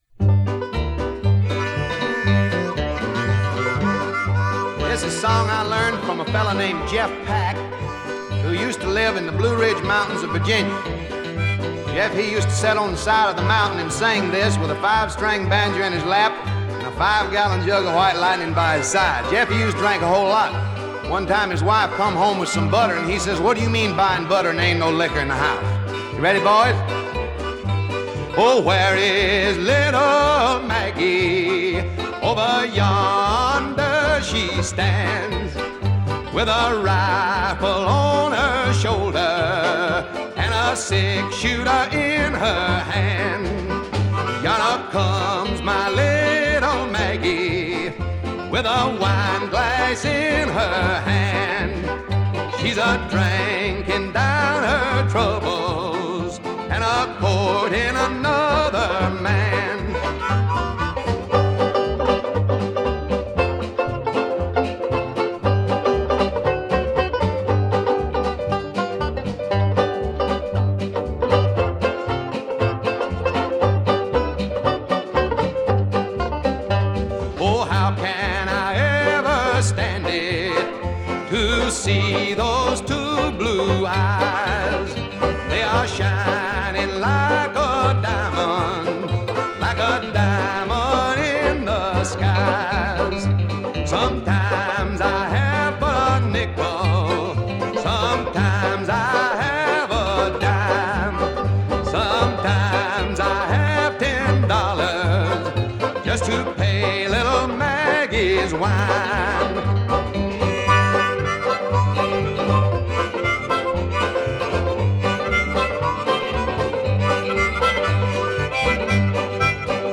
Image result for Andy Griffith Shouts The Blues And Old Timey Songs